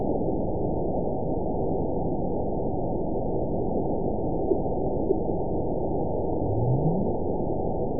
event 921815 date 12/19/24 time 06:41:29 GMT (4 months, 1 week ago) score 9.59 location TSS-AB01 detected by nrw target species NRW annotations +NRW Spectrogram: Frequency (kHz) vs. Time (s) audio not available .wav